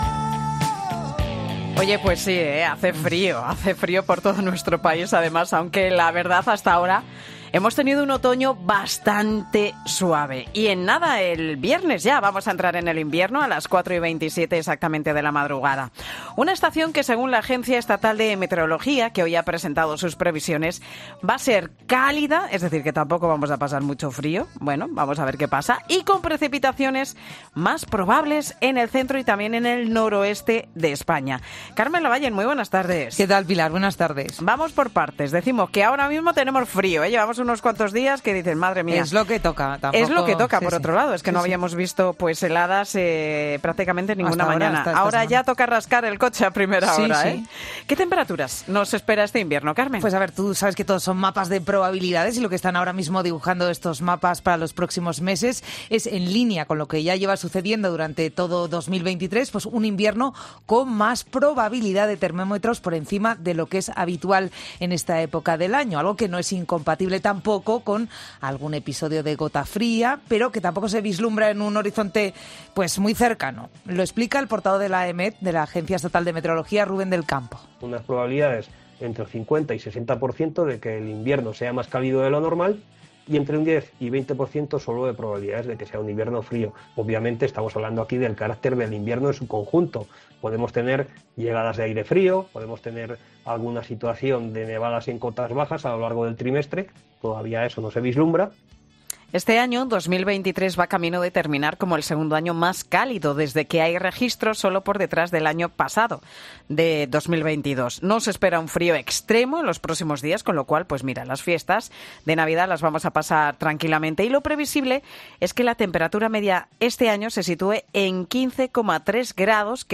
Un meteorólogo de la AEMET cuenta en 'Mediodía COPE' cómo será el invierno